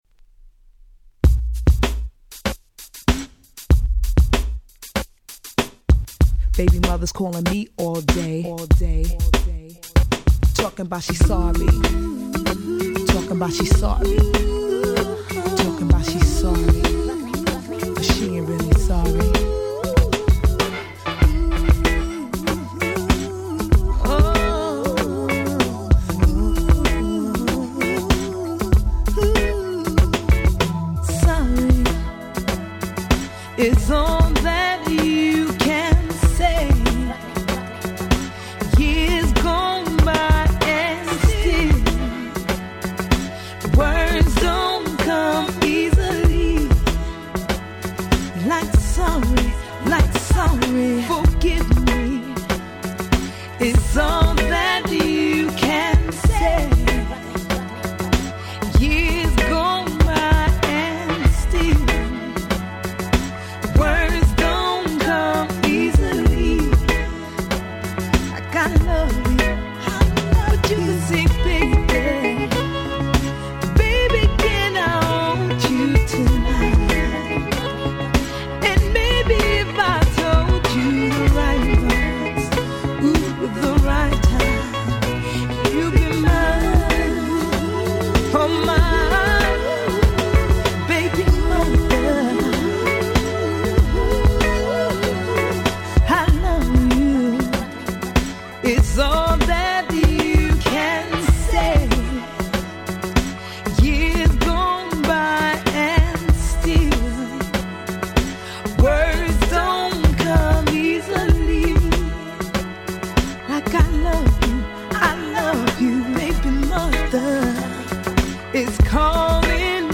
98' Smash Hit R&B !!
両面共にLauryn Hillを思わせる様なEarthyで温かいナンバー。